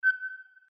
push_sound_03.mp3